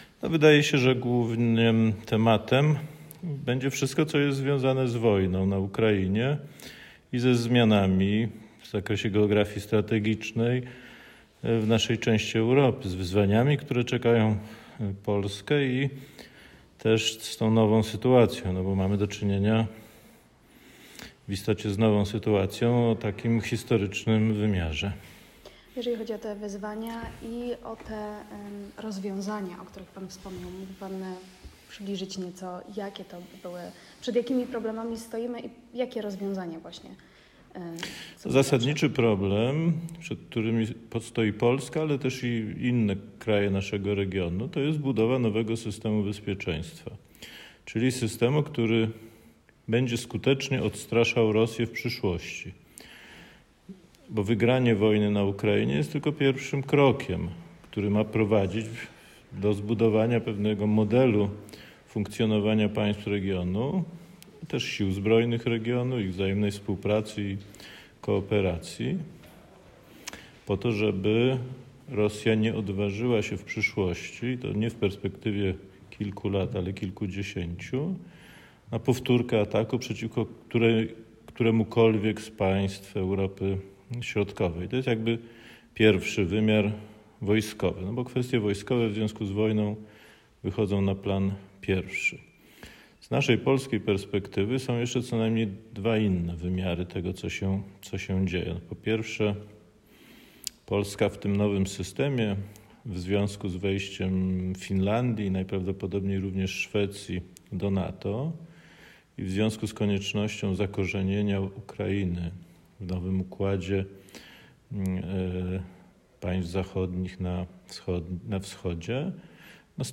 w Zgierzu na spotkaniu autorskim